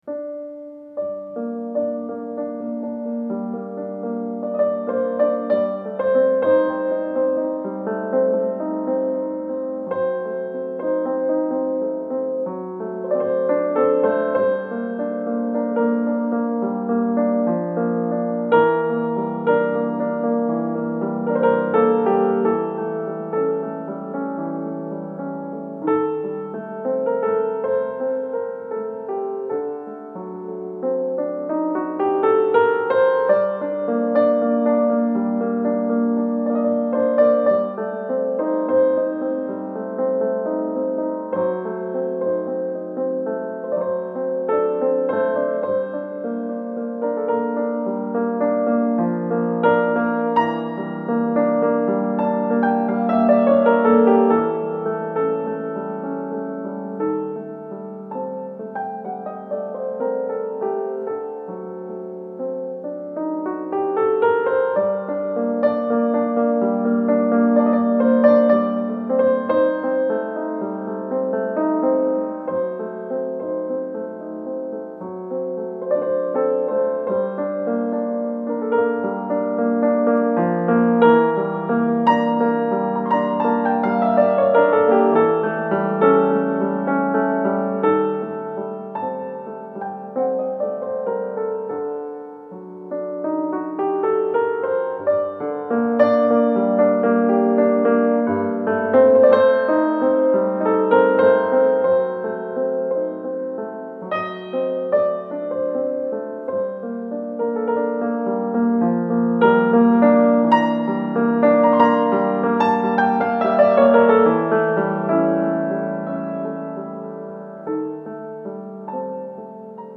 آهنگ لایت فوق العاده آرامبخش وشنیدنی تقدیم به شما کاربر عزیز
اثری زیبا و فوق العاده آرامبخش
[نوع آهنگ: لایت]